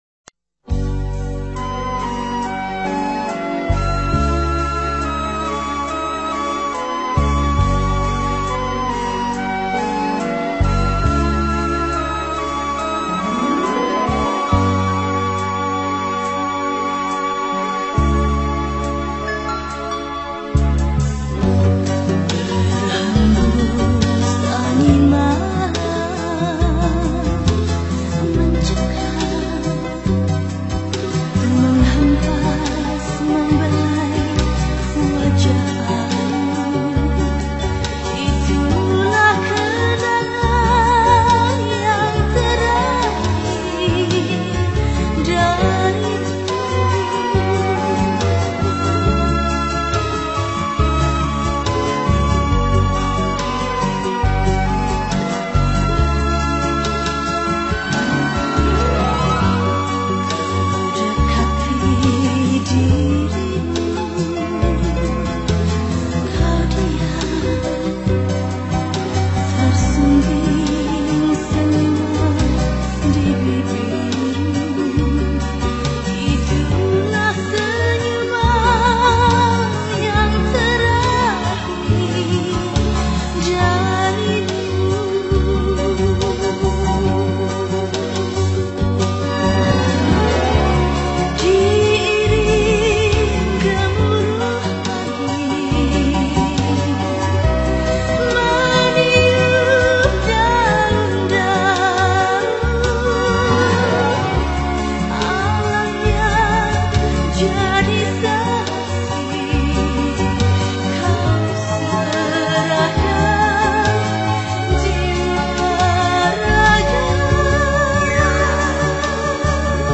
penyanyi pop jawa